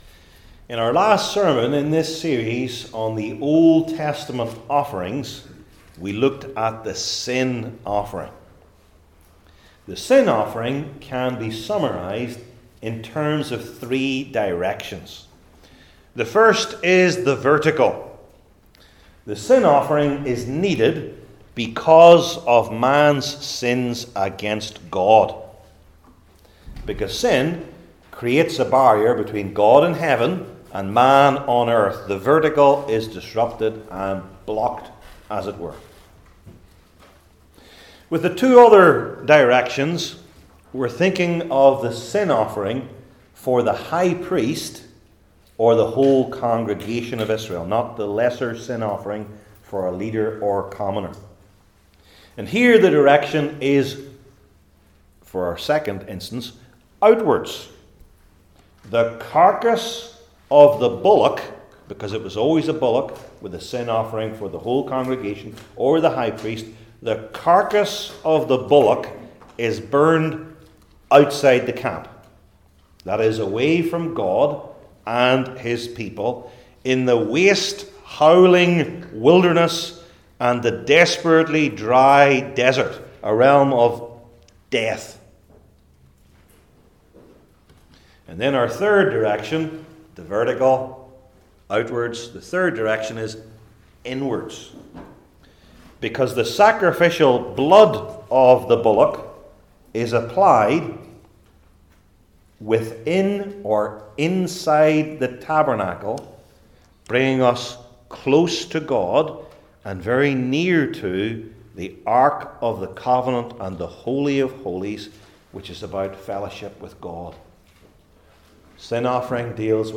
Leviticus 5:14-6:7 Service Type: Old Testament Sermon Series I. The Need for the Trespass Offering II.